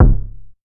CDK Weird Kick.wav